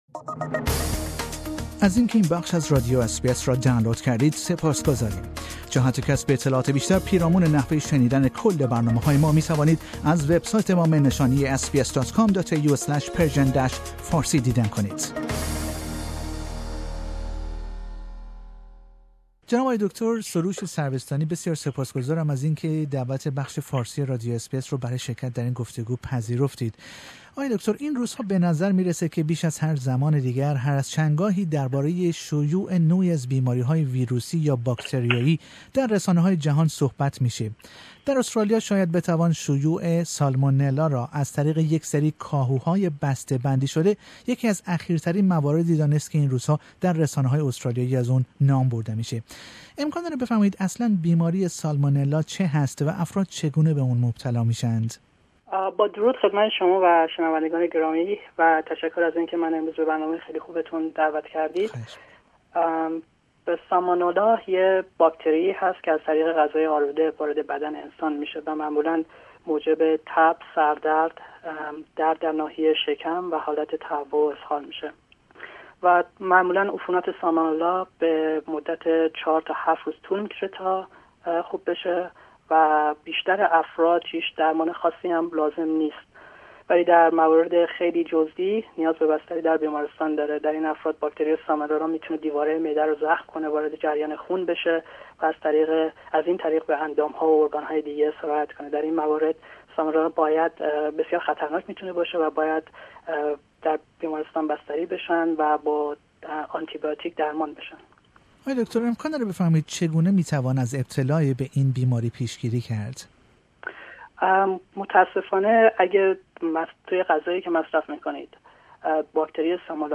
در گفتگو با بخش فارسی رادیو اس بی اس